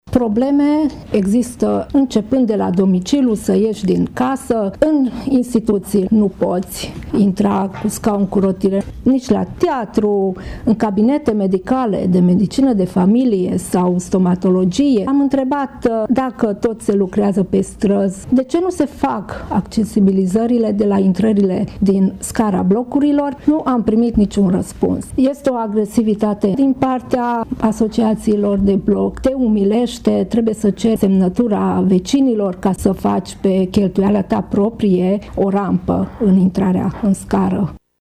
Aceasta este concluzia mesei rotunde de astăzi de la sediul Fundației Alpha Transilvană din Tîrgu-Mureș, la care au participat reprezentanți ai ONG-urilor care au ca obiect drepturile persoanelor cu dizabilități și reprezentanți ai administrației locale.